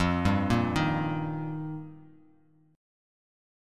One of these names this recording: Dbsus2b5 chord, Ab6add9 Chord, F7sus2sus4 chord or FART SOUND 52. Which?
F7sus2sus4 chord